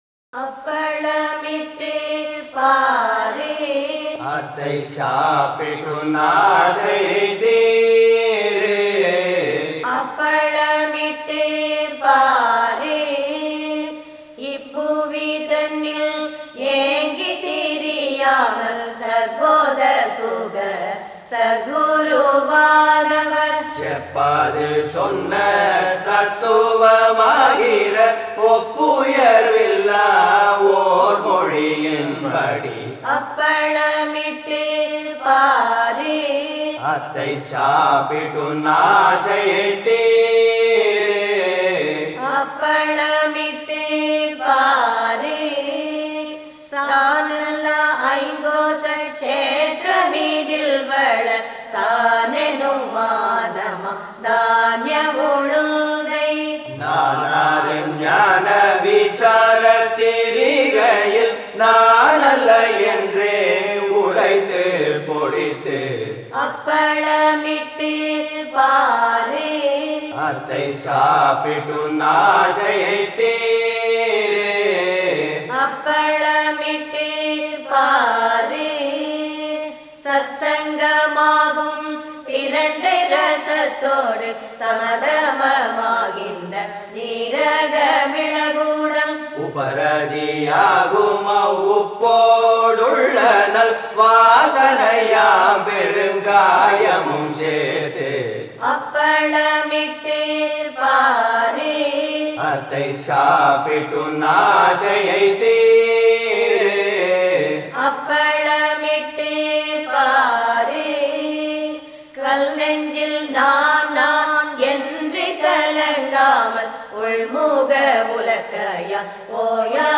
Tamil Parayana de - Sri Ramanasramam